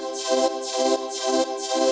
SaS_MovingPad02_125-C.wav